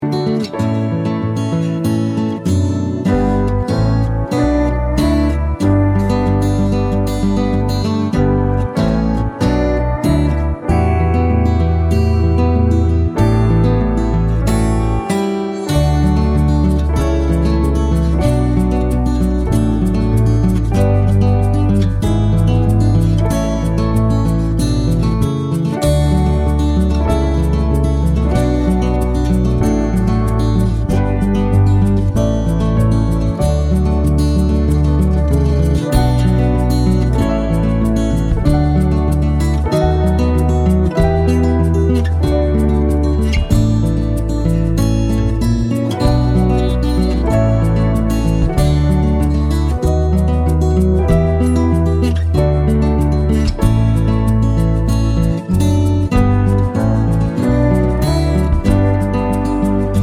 Minus High Octave BV Pop (2020s) 3:15 Buy £1.50